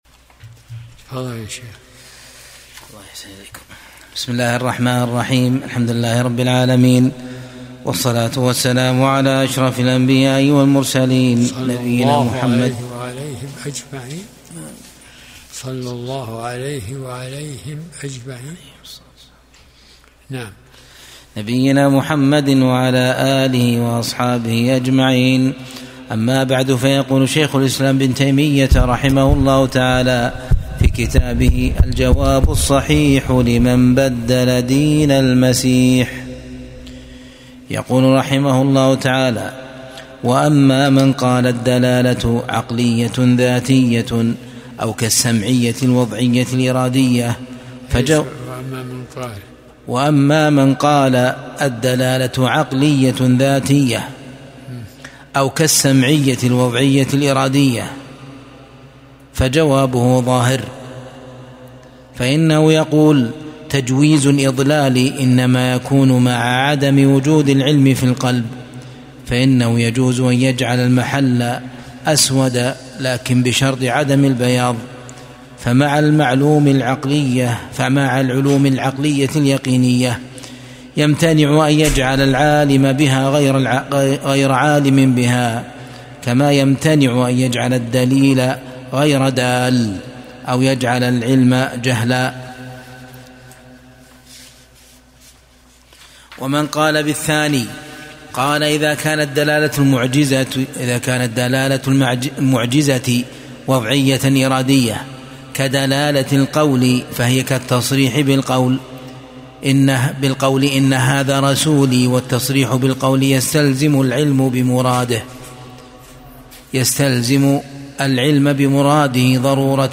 درس الأربعاء 53